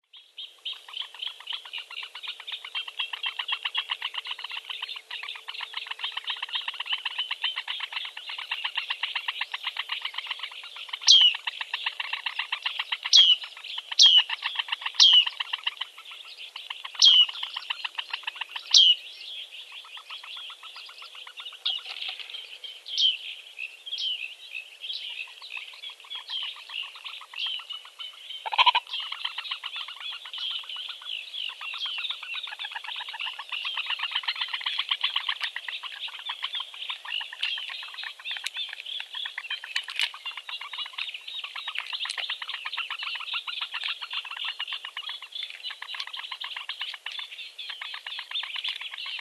Звук щебетания птенцов выпи большой